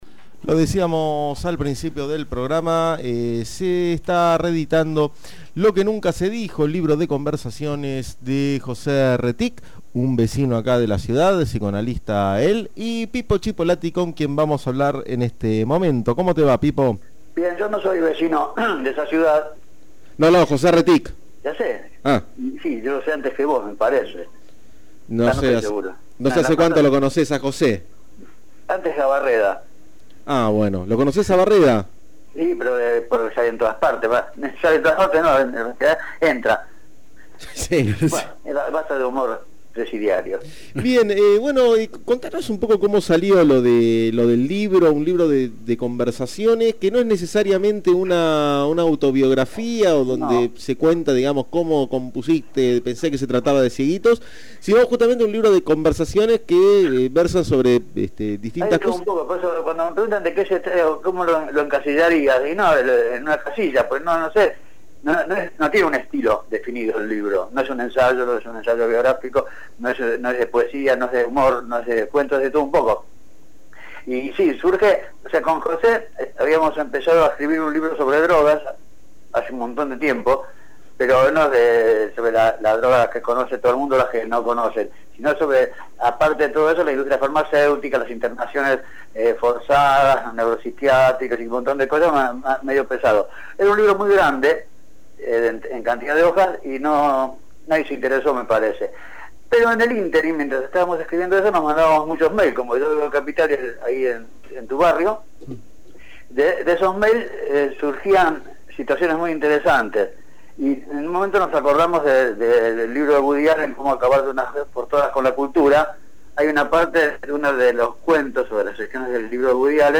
En diálogo con Manjares en la Azotea, el compositor repasó los aspectos del trabajo y su presente musical.